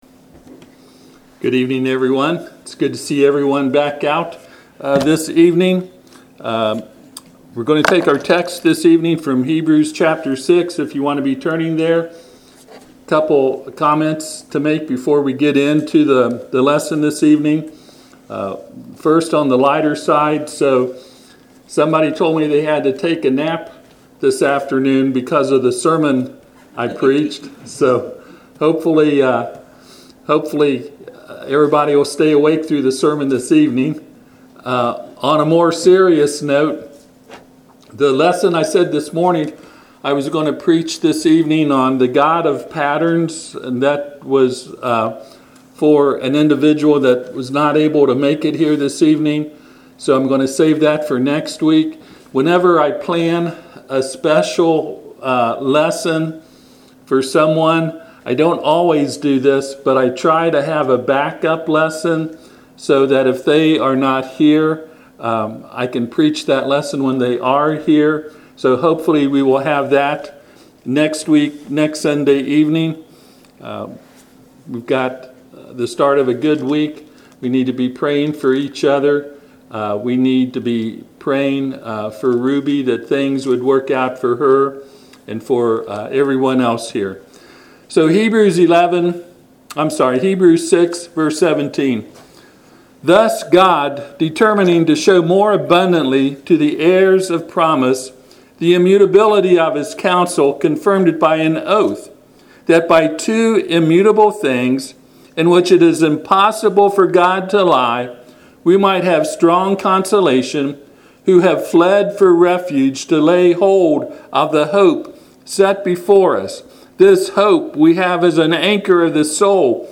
Passage: Hebrews 6:17-19 Service Type: Sunday PM